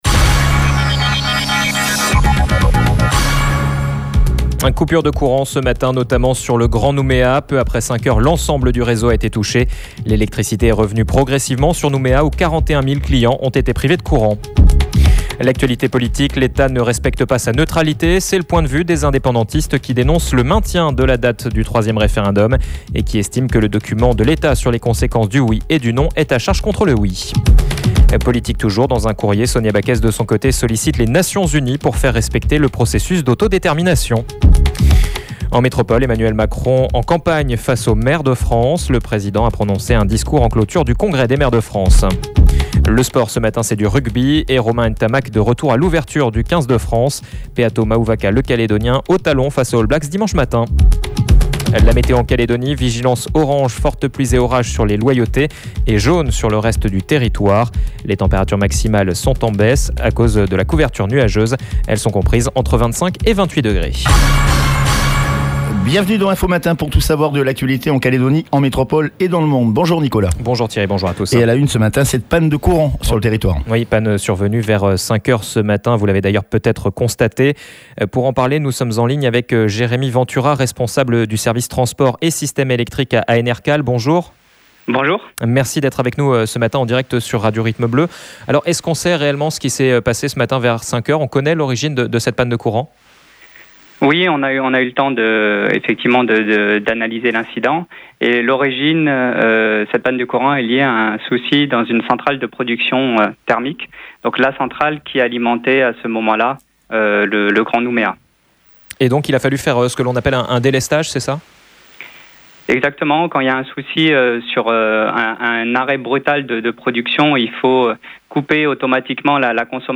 JOURNAL : VENDREDI 19/11/21 (MATIN)